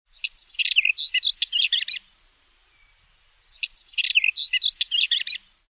Download the song of a whitethroat to use as your ringtone.